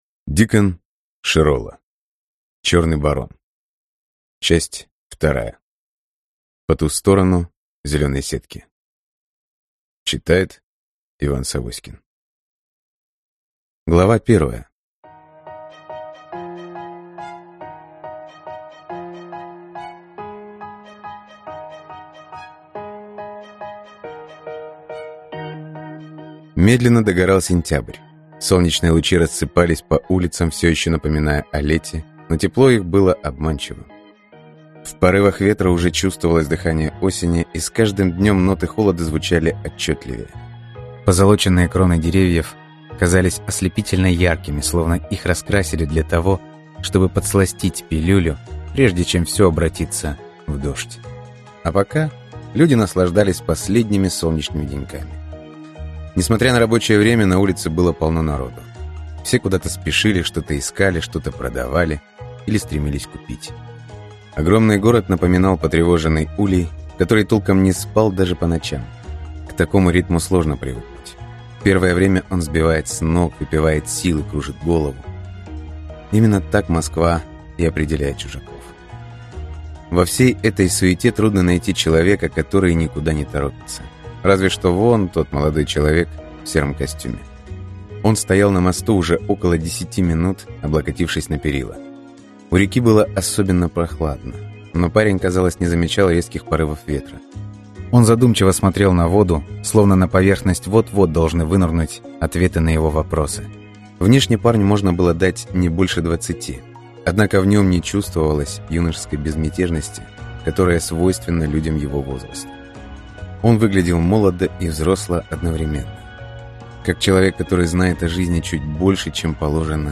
Аудиокнига По ту сторону зелёной сетки | Библиотека аудиокниг